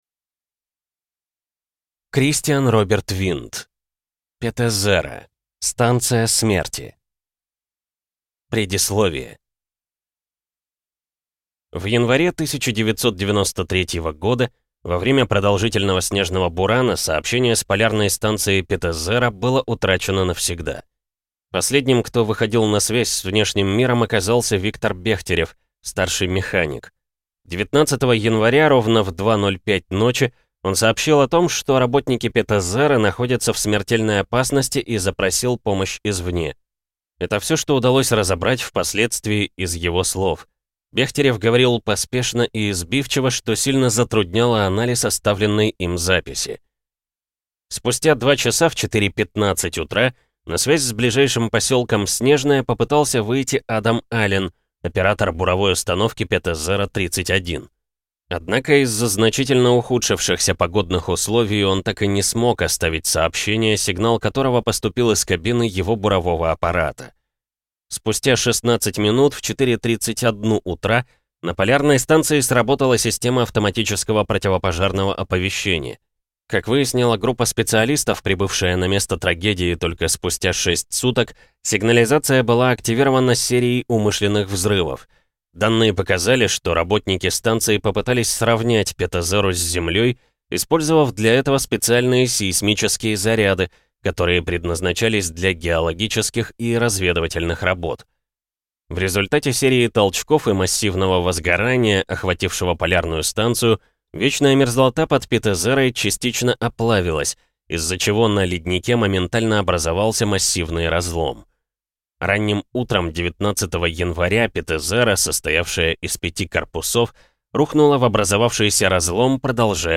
Аудиокнига Петезера: станция смерти | Библиотека аудиокниг